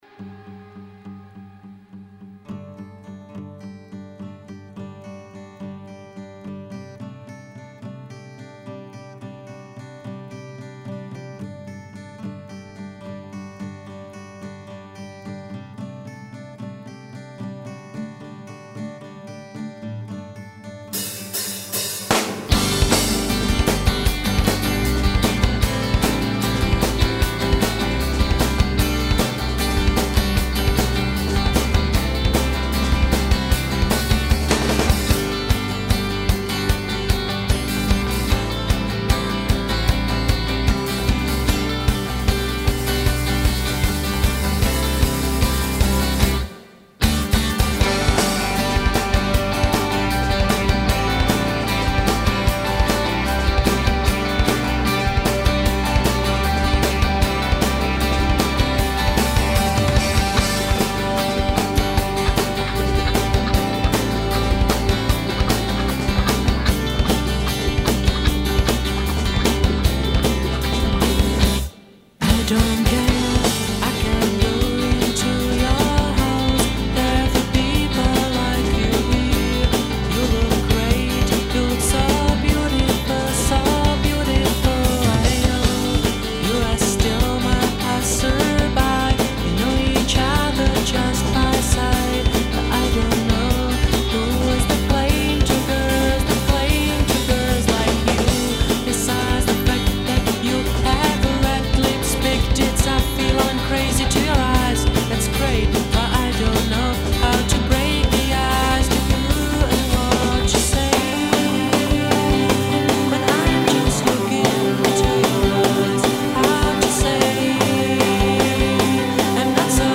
indiepoppy